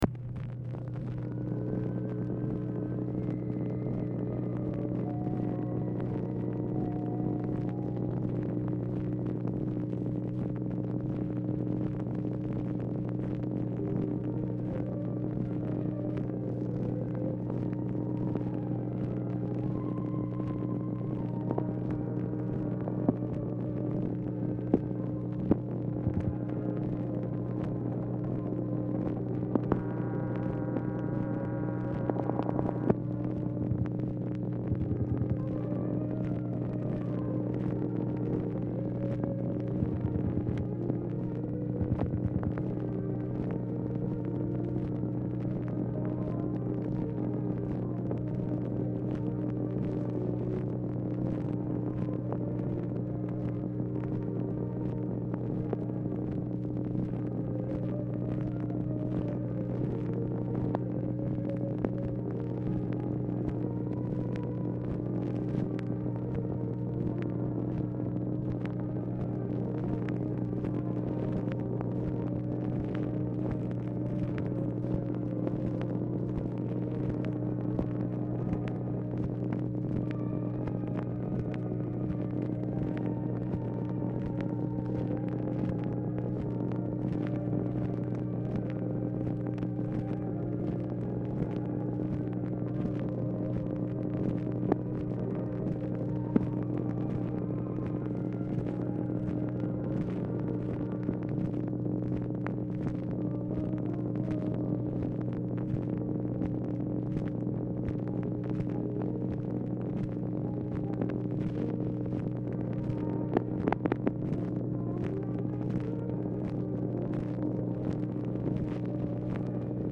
Telephone conversation # 7306, sound recording, OFFICE NOISE, 4/1/1965, time unknown | Discover LBJ
MUSIC AUDIBLE IN BACKGROUND
Dictation belt